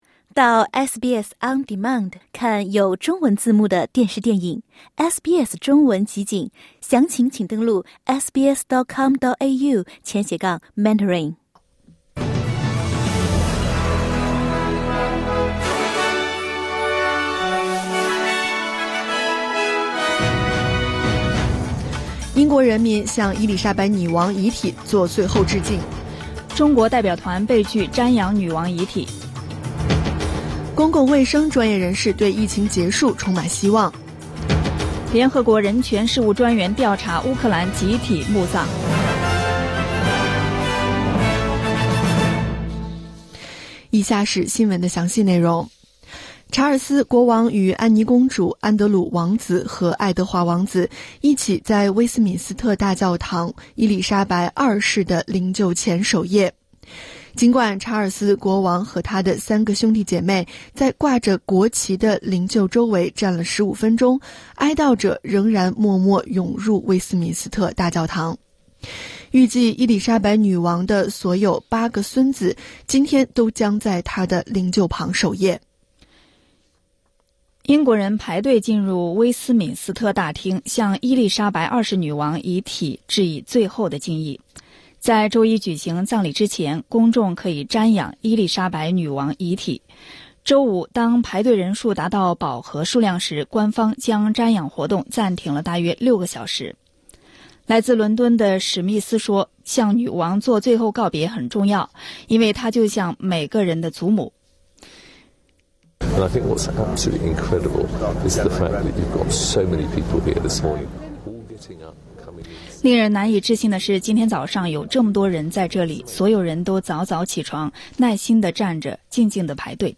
SBS早新闻（9月17日）